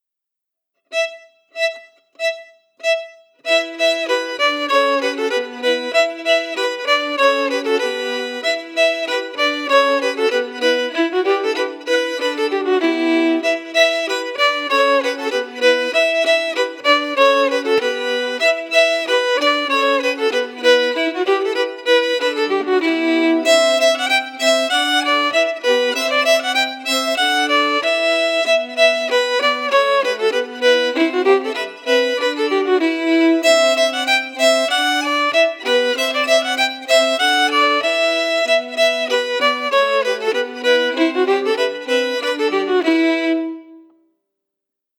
Key: Edor
Form: Reel
Melody emphasis
Source: Trad.
Region: Wales